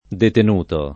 vai all'elenco alfabetico delle voci ingrandisci il carattere 100% rimpicciolisci il carattere stampa invia tramite posta elettronica codividi su Facebook detenuto [ deten 2 to ] part. pass. di detenere , agg., s. m.